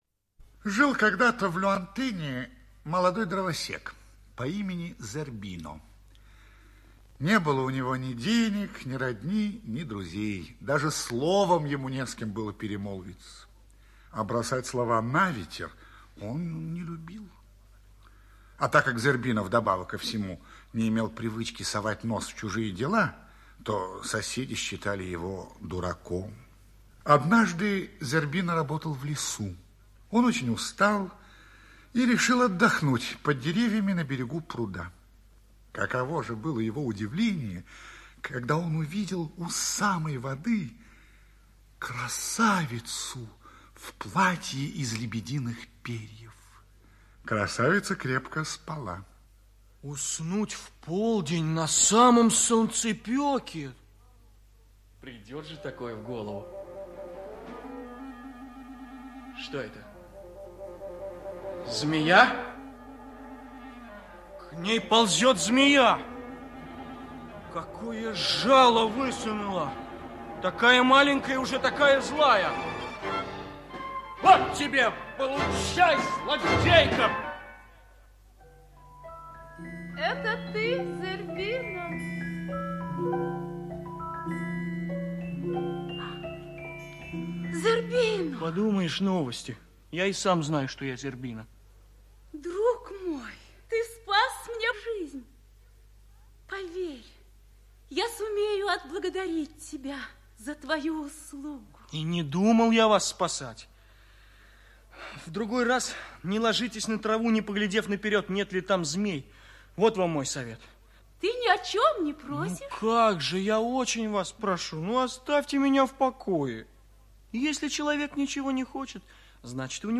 Зербино-дровосек - аудиосказка Лабулэ - слушать онлайн